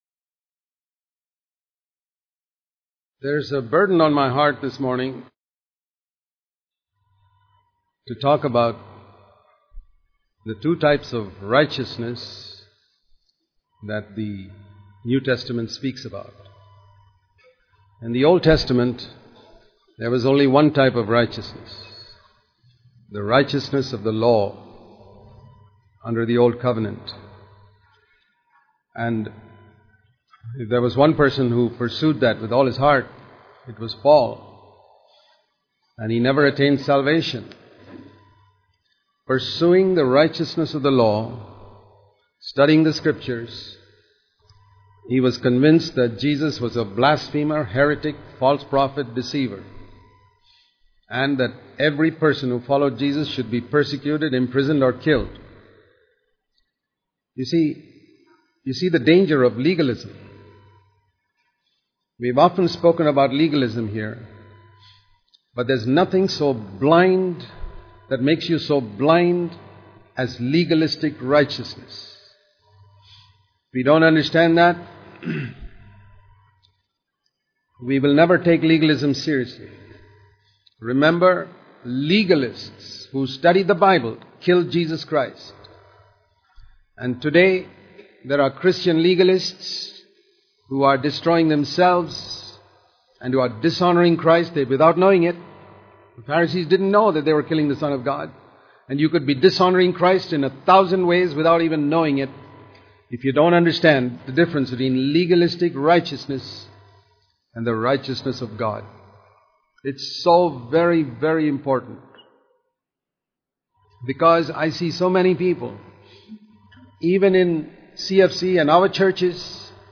Religious or Spiritual